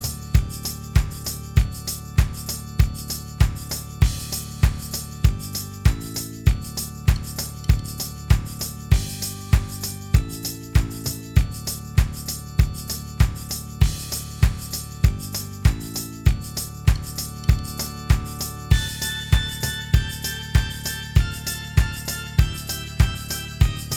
Minus All Guitars Pop (2010s) 2:58 Buy £1.50